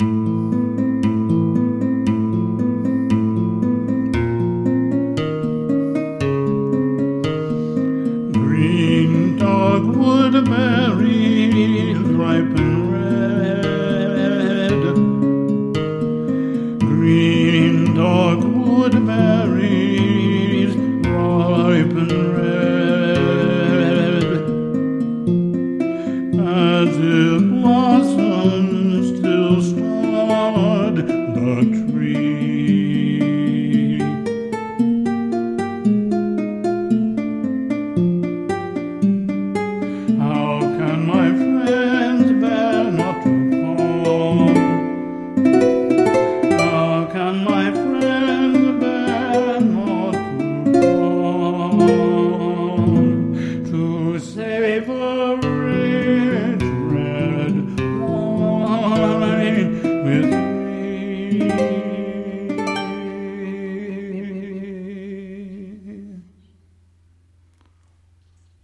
for solo voice and guitar
for solo voice and piano